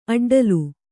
♪ aḍḍalu